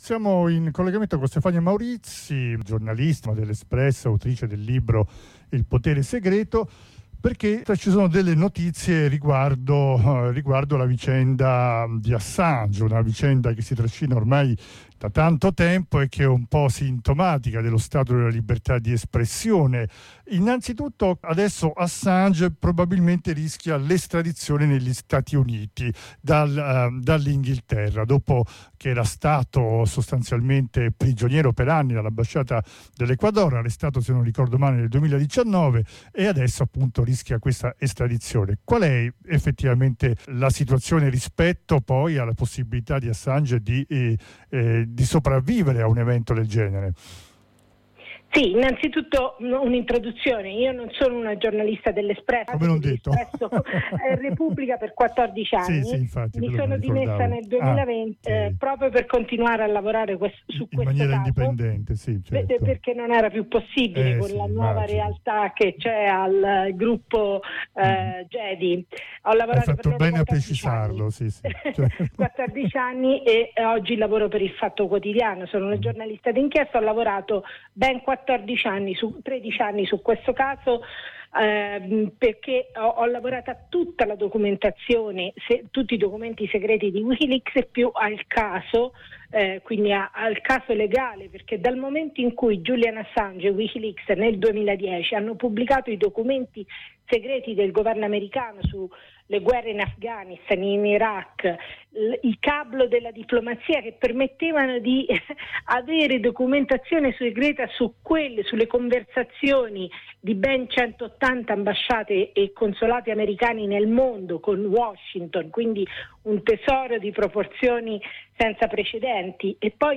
Ne parliamo con Stefania Maurizi giornalista che ha seguito da vicino Assange e con cui ha lavorato nella pubblicazione dei files di Wikileaks e autrice del libro “il potere segreto”che dipana le fila della vicenda l’autrice ha usato le leggi di accesso agli atti, che vanno sotto il nome di Freedom of Information, scoprendo documenti che rivelano gli attacchi a Julian Assange e il coinvolgimento dell’apparato militare industriale americano nell’insabbiamento dei crimini di guerra resi pubblicida Wikileaks.